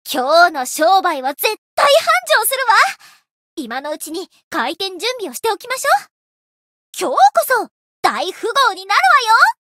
灵魂潮汐-叶月雪-情人节（相伴语音）.ogg